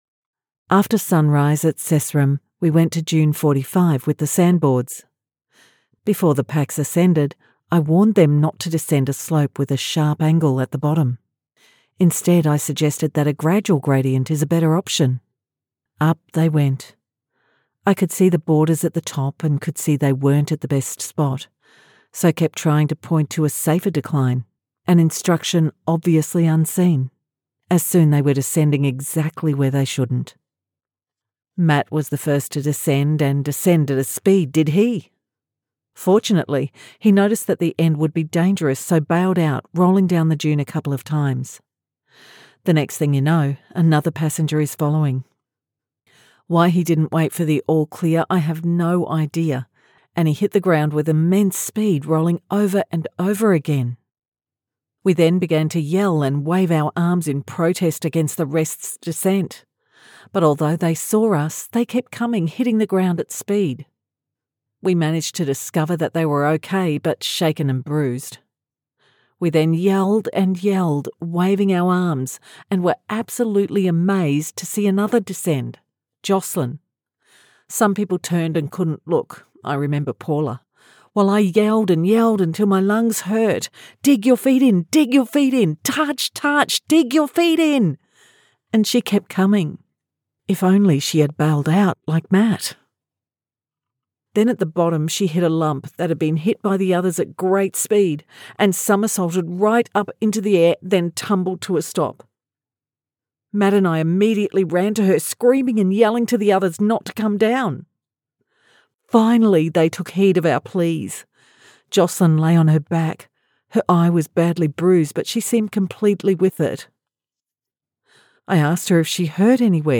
Never any Artificial Voices used, unlike other sites.
Female
English (Australian)
Adult (30-50), Older Sound (50+)
Narration Of A Letter - Sample
All our voice actors have professional broadcast quality recording studios.